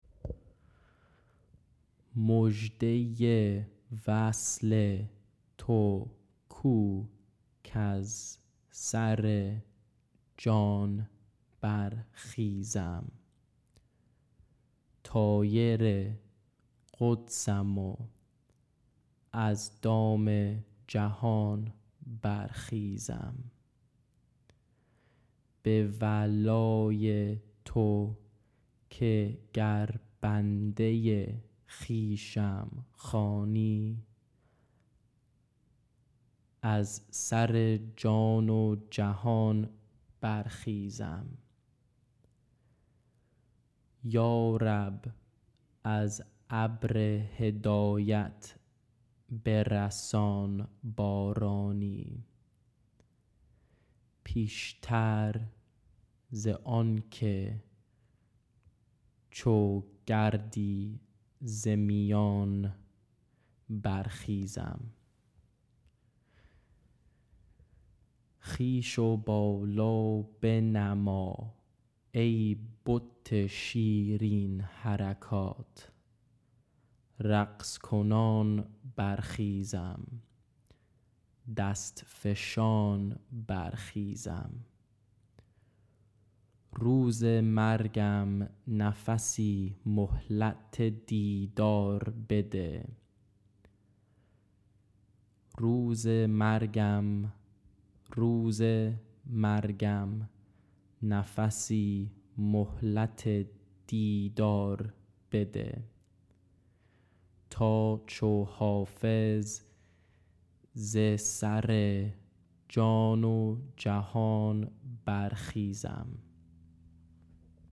Recording of me reciting the full poem
bar-xizam_text-pronunciation.mp3